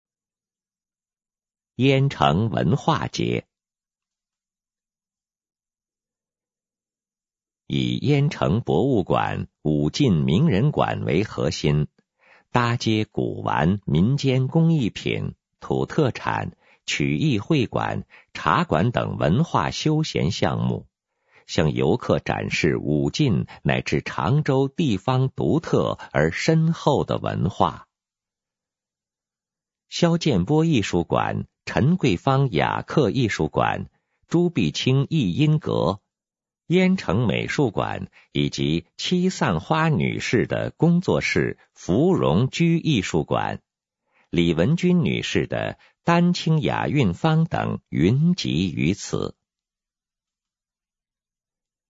语音导览